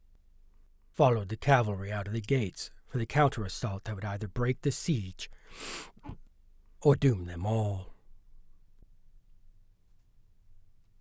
Low room tone noise
Scarlett Solo 2
AT2020 mic
This is the raw recording. No noise removal or filtering.
There’s a tone at 16Hz, one equal loudness at 23Hz and then a quieter one at 31Hz.